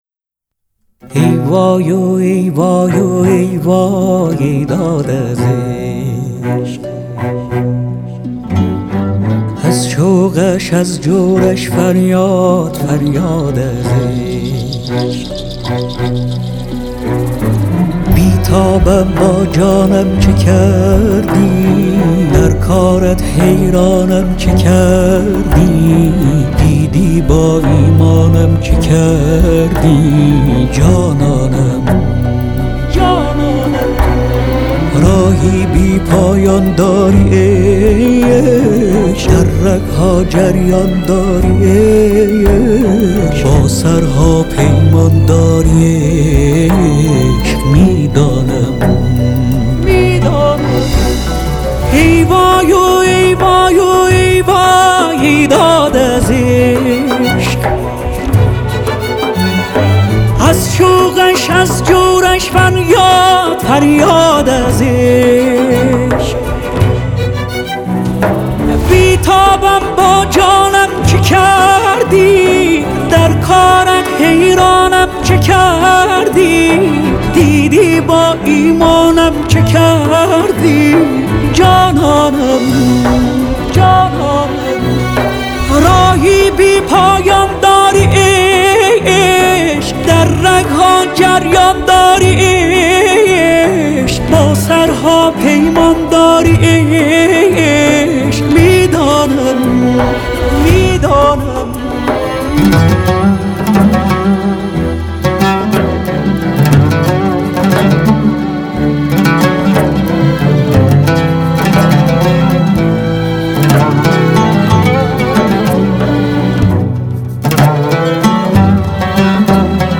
ویلن ویولا
ویلنسل
عود
پرکاشن و سازهای زمینه